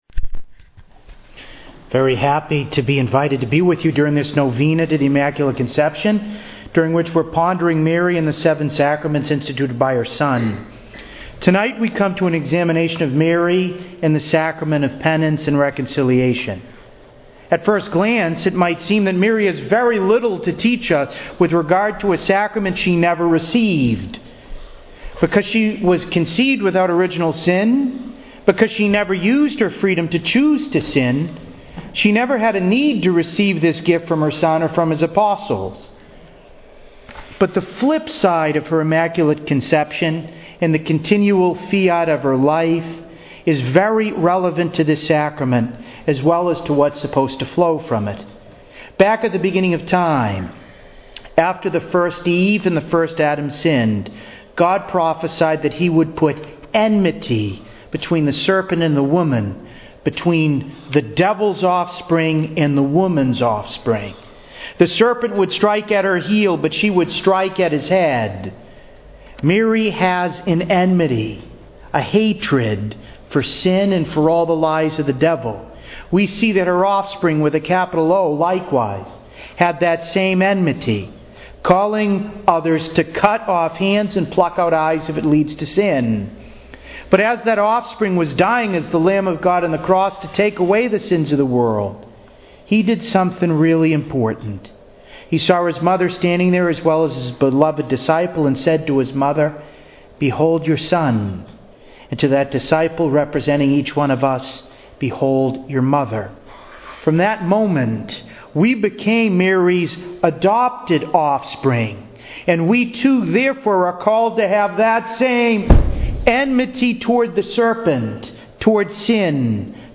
[This is a talk within a novena focused on Mary’s role in the Seven Sacraments]
Novena to Our Lady of the Immaculate Conception Holy Family Church, East Taunton, MA